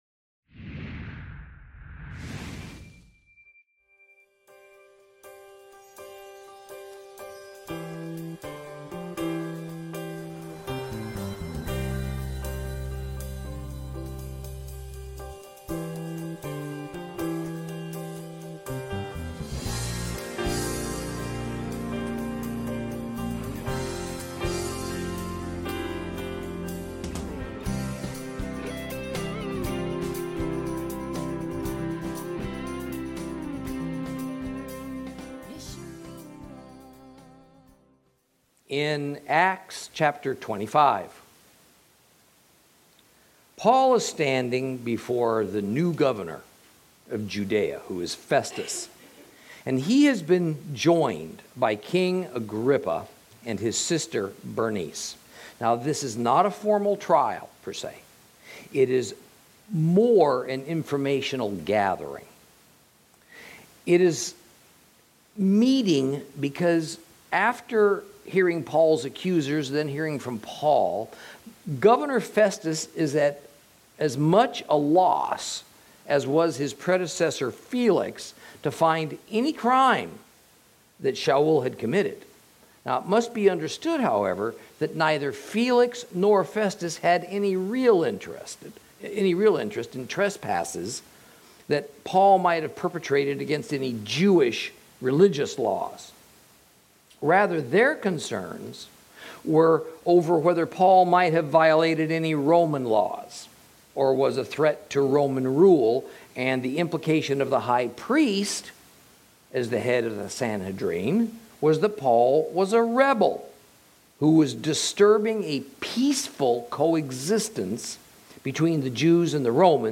Lesson 53 Ch25 Ch26 - Torah Class